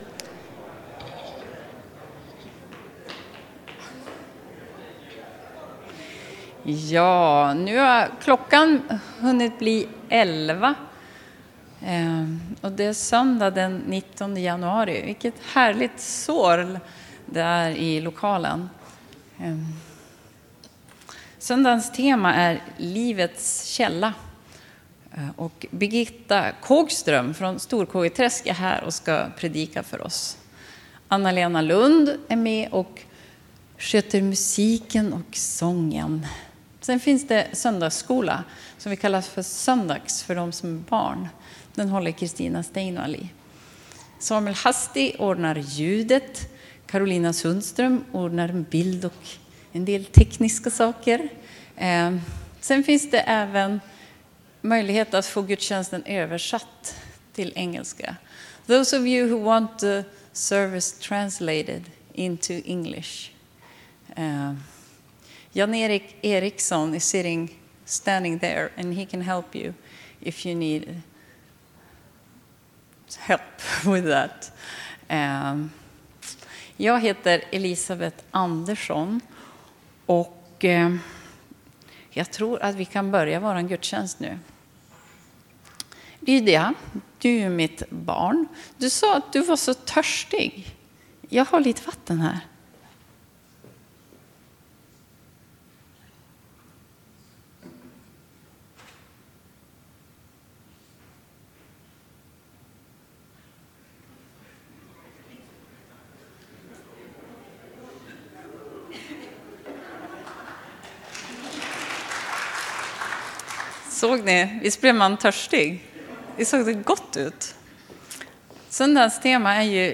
Gudstjänst 19 januari
Hon avslutade med att sjunga välsignelsen.